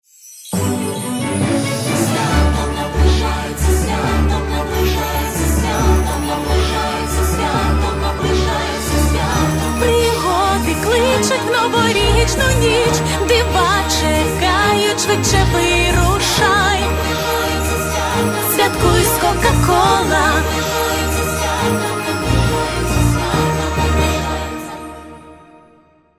• Качество: 128, Stereo
праздничные
колокольчики
Реклама на украинском